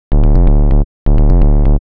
• Techno Q Short Mid Bass.wav
Techno_Q_Short_Mid_Bass__Pb3.wav